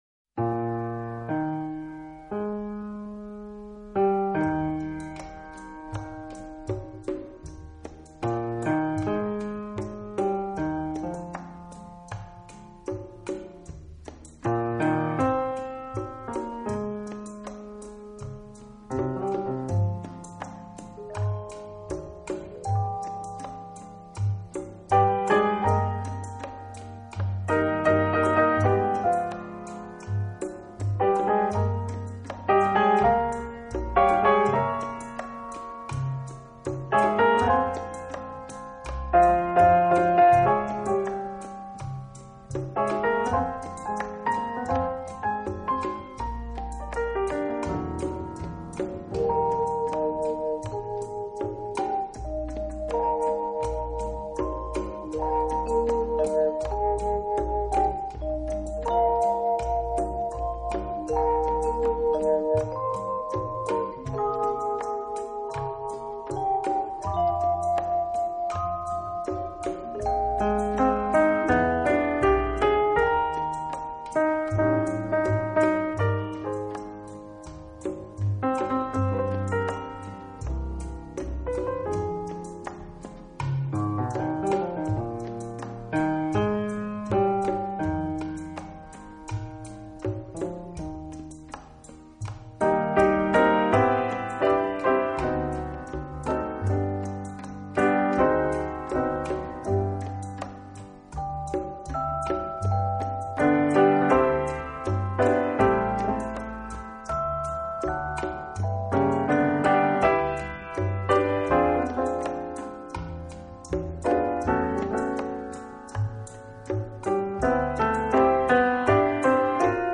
【轻音乐】
演奏以轻音乐和舞曲为主。